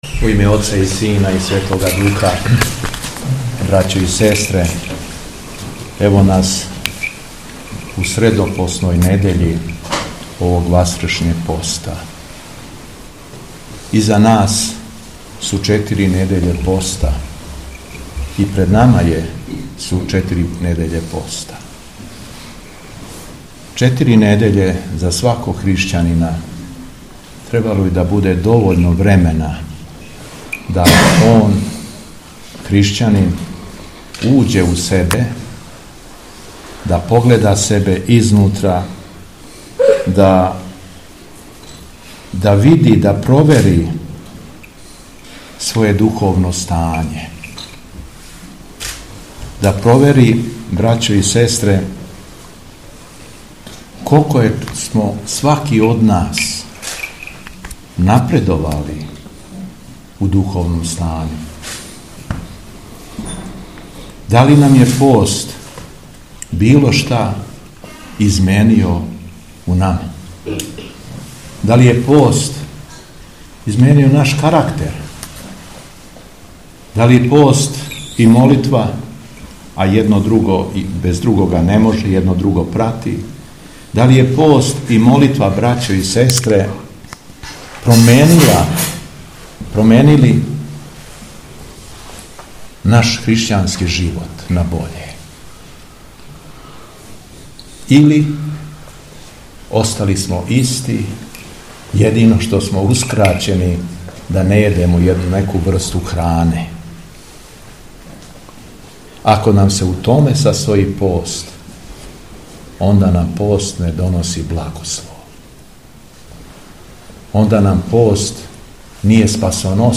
Беседа Његовог Високопреосвештенства Митрополита шумадијског г. Јована
Пре приступања Светом Причешћу верном народу Великих Крчамара, Доњих и Горњих Јарушица, Бораца и околине митрополит шумадијски Господин Јован се обратио следећим речима: